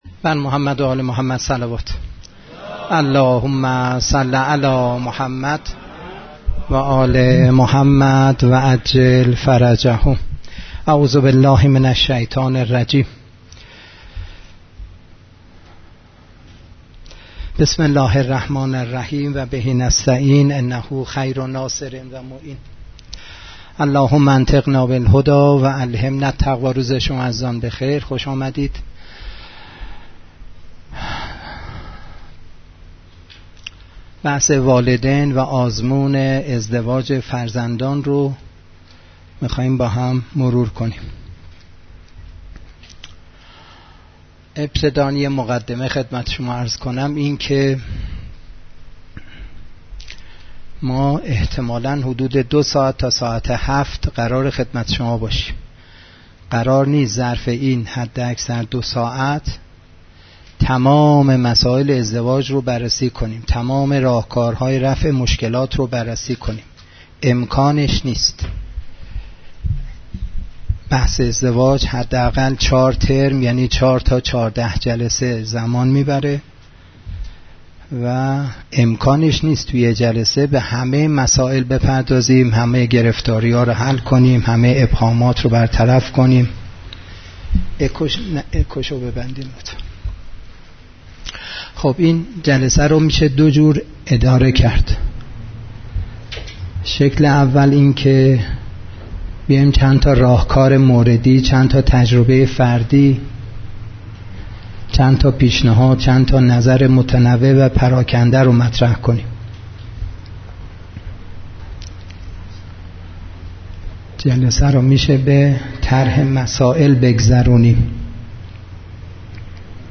کارگاه والدین دلسوز، امانتداران مهدوی _ جلسه دوم